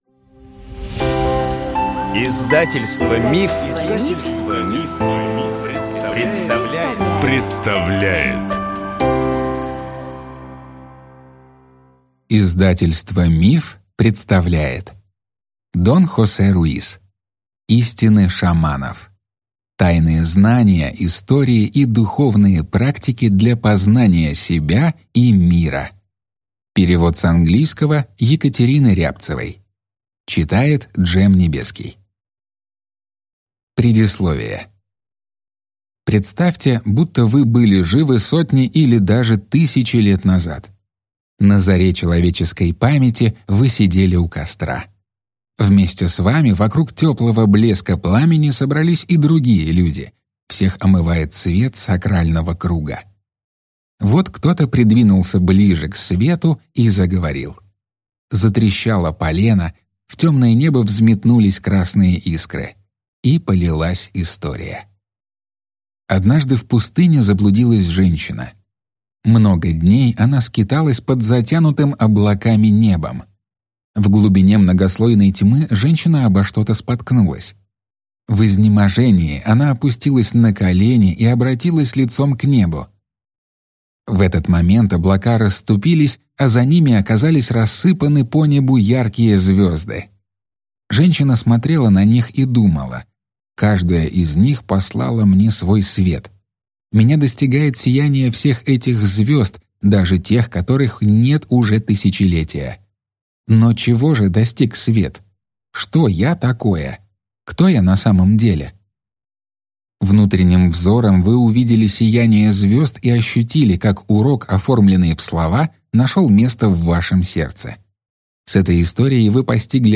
Аудиокнига Истины шаманов. Тайные знания, истории и духовные практики для познания себя и мира | Библиотека аудиокниг